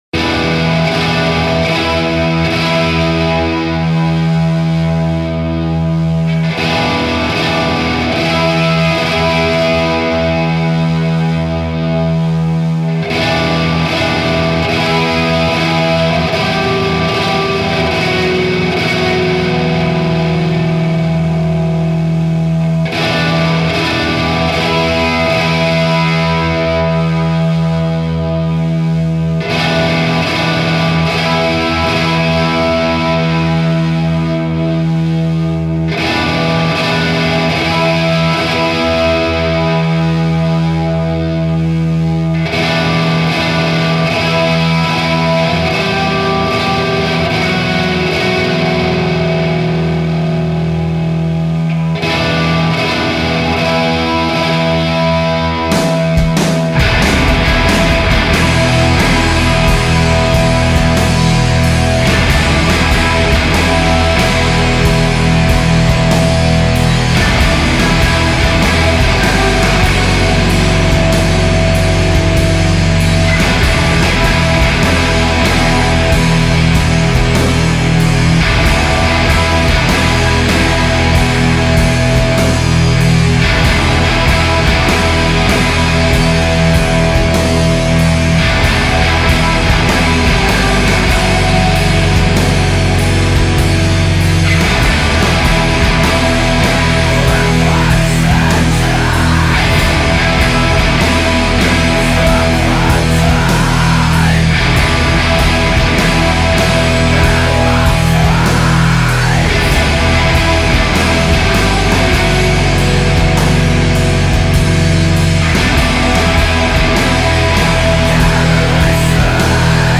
Recorded at Mission Hall Studio July 2004